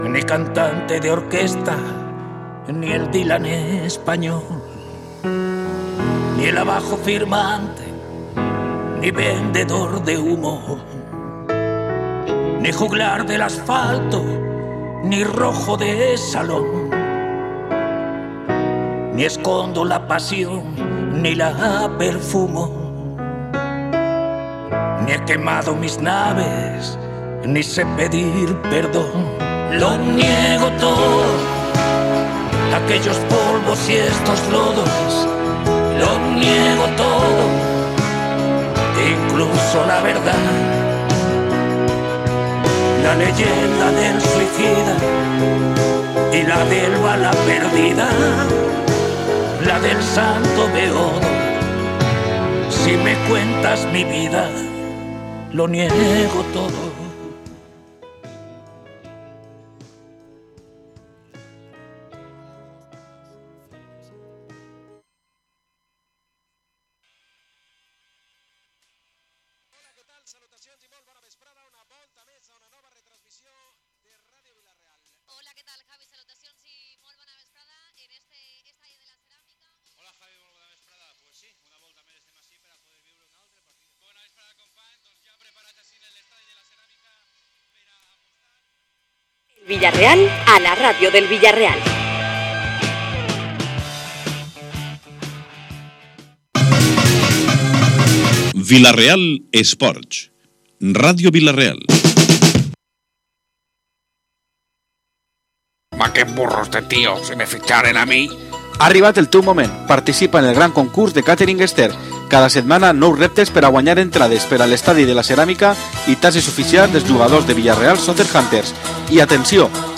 Así debatimos en forma de tertulia tras la victoria en Girona del Villarreal.